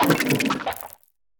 Cri de Selutin dans Pokémon HOME.